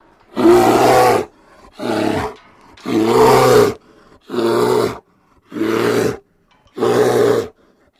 Kodiak Bear Roar And Breath Extract